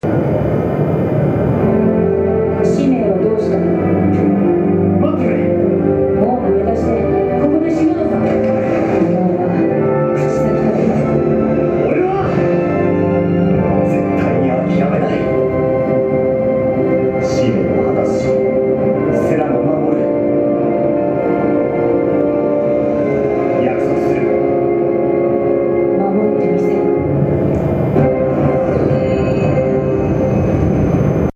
FFXIII的那段对白的录音 不让偷拍，偷录音可以吧 。